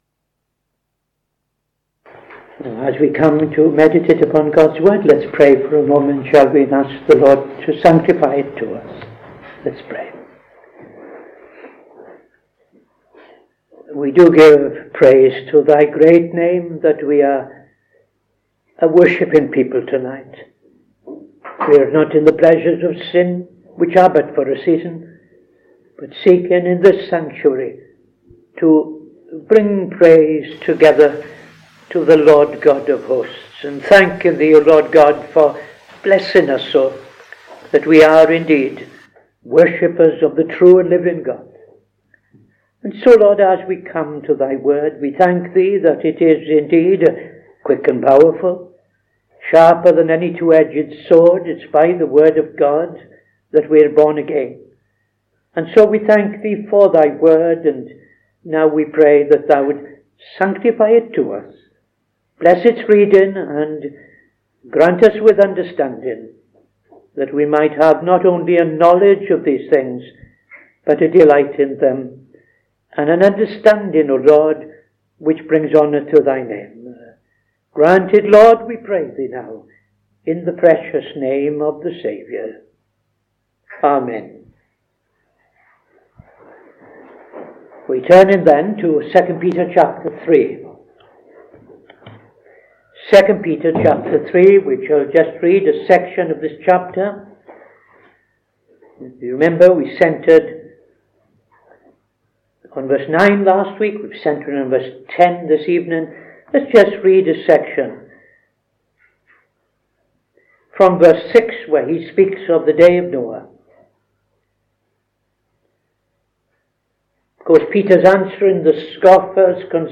Saturday Sermon - TFCChurch
Opening Prayer and Reading II Peter 3:6-12